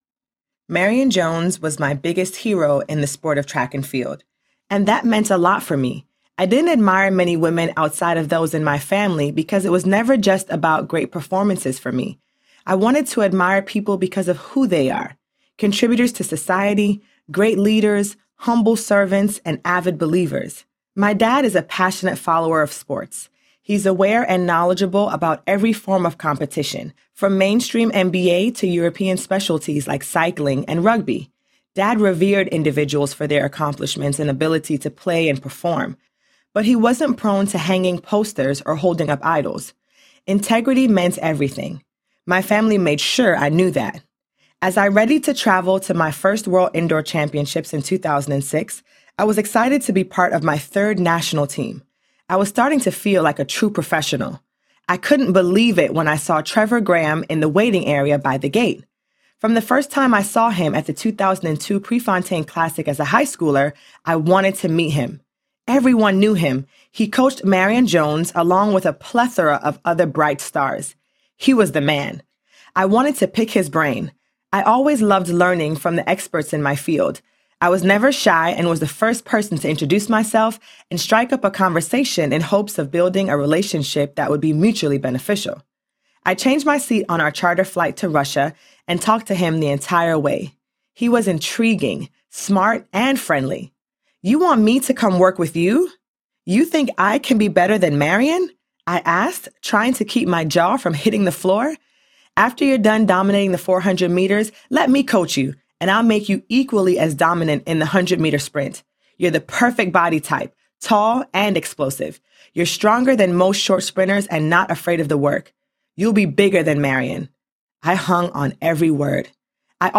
Chasing Grace Audiobook
Narrator
3.5 Hrs. – Unabridged